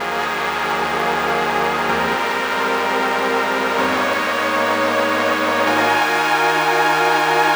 Domedark Ab 127.wav